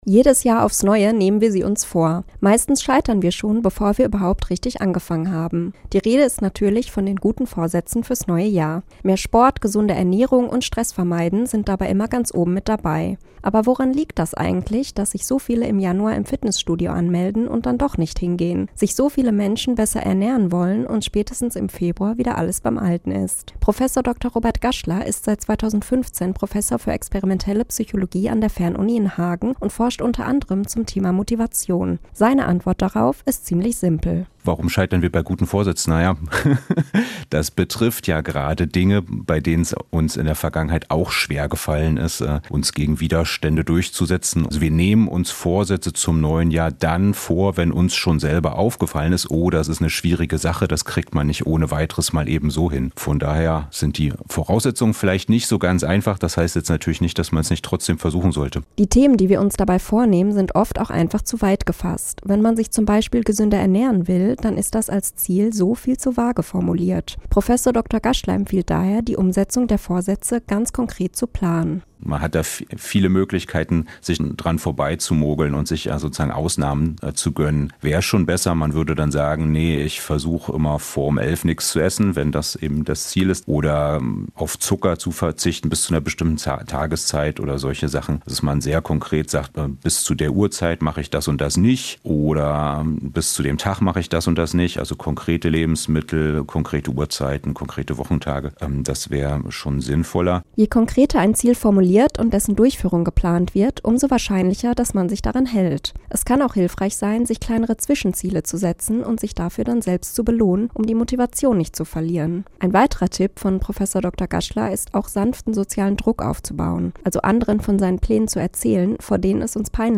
Wir haben mit einem Psychologie-Professor über diese Fragen gesprochen.